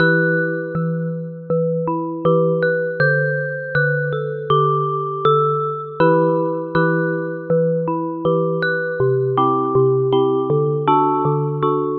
合成器电颤琴
Tag: 80 bpm Chill Out Loops Synth Loops 2.02 MB wav Key : E